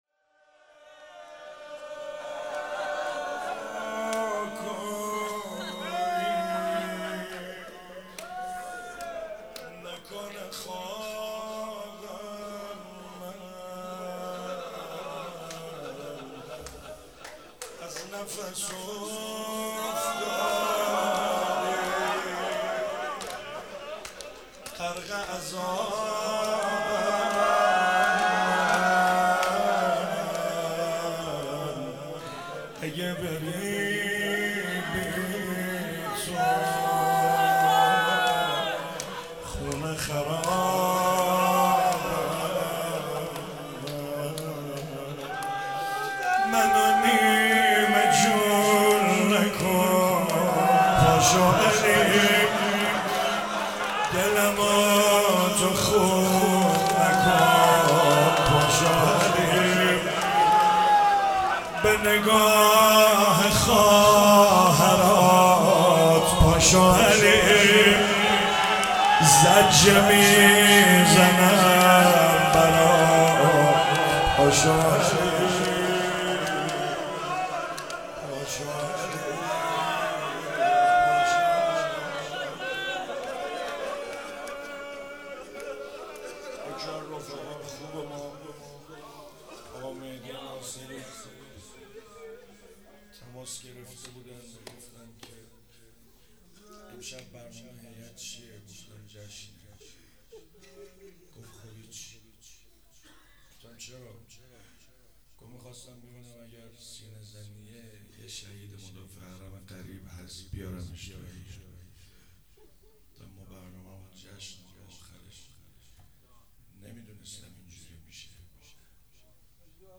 چهاراه شهید شیرودی حسینیه حضرت زینب (سلام الله علیها)
شور- منو نیمه جون نکن پاشو علی